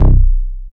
31SYN.BASS.wav